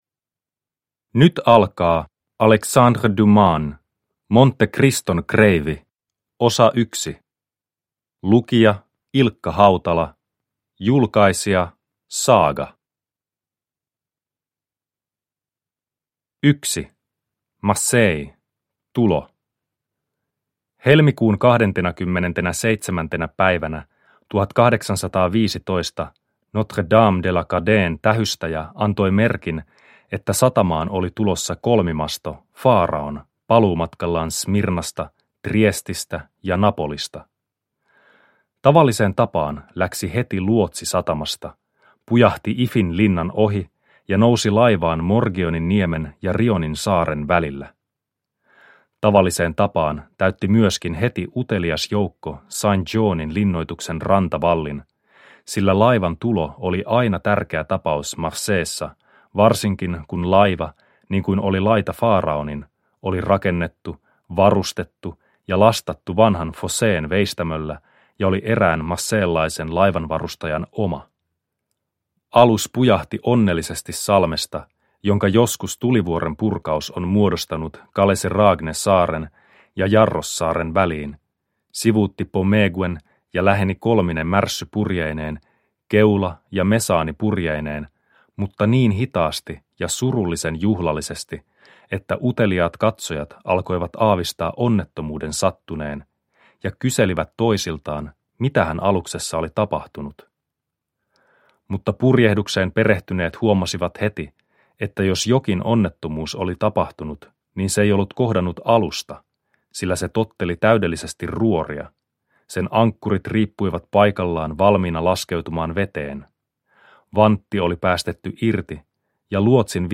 Monte-Criston kreivi 1 – Ljudbok – Laddas ner